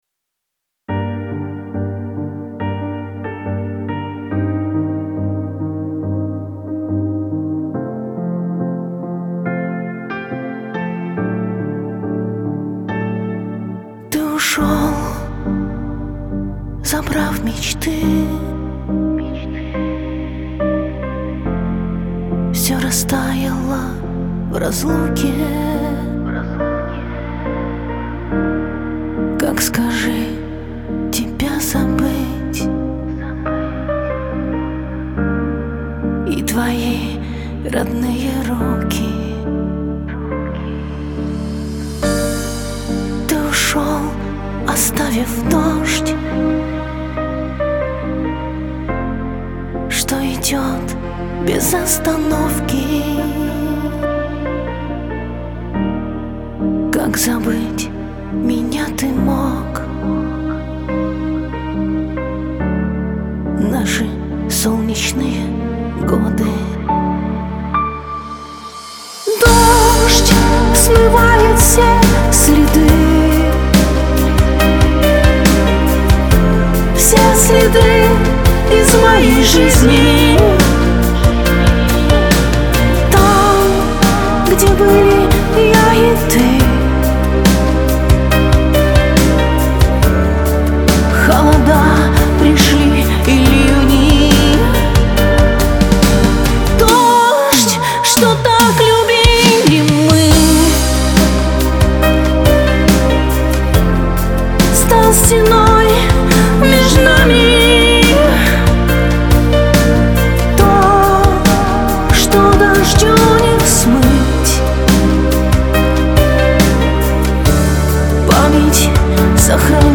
Лирика , эстрада
диско
pop